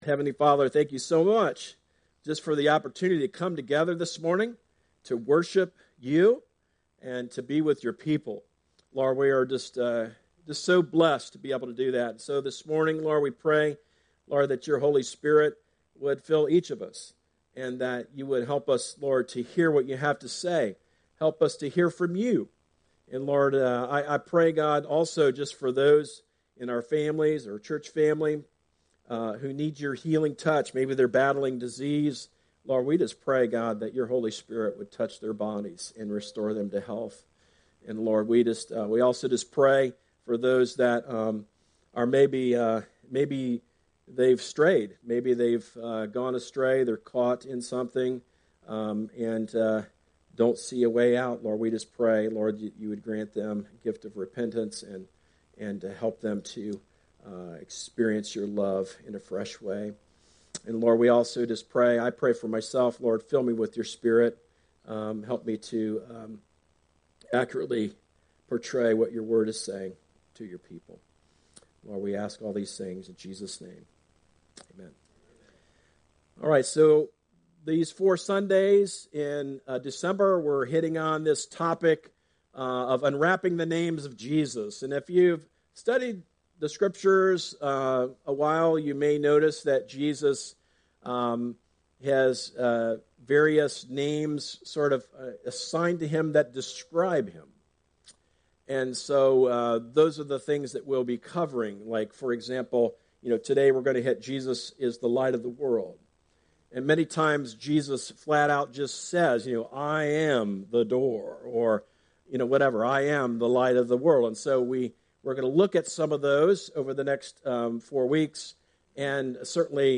Sermons - Darby Creek Church - Galloway, OH